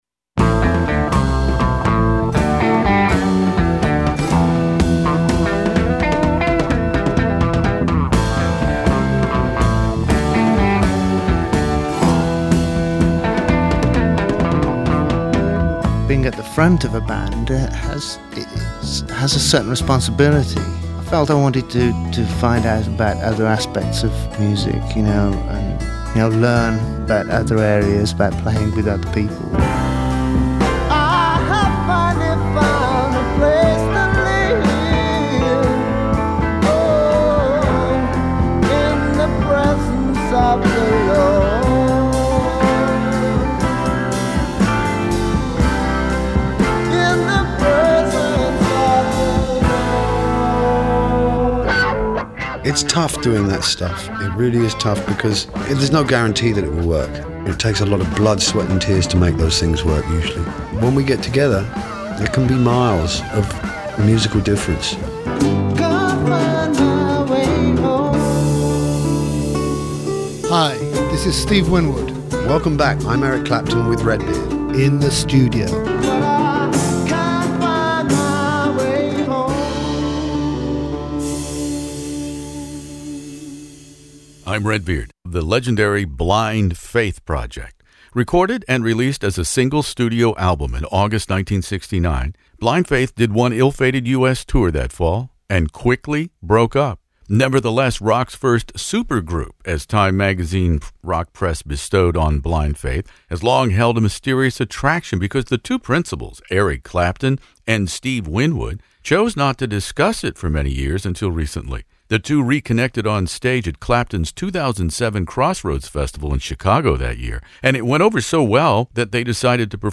Eric Clapton and Steve Winwood interviews for Blind Faith